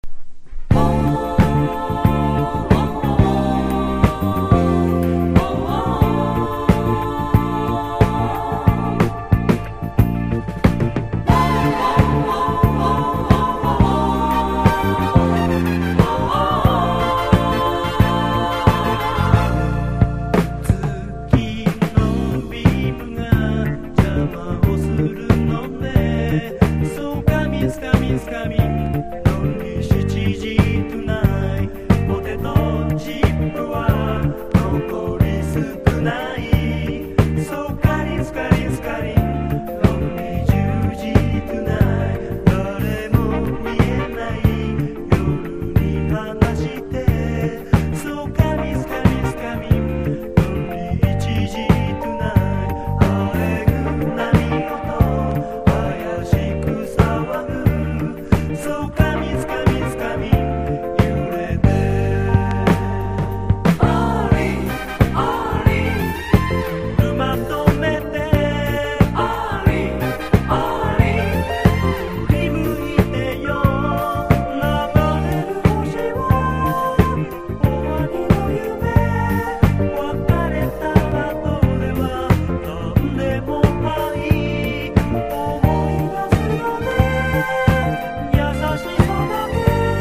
高い演奏力と、大所帯の迫力とファルセット･ヴォーカルが格好良い和製ディスコ･ファンク～FUSION FUNKの名作。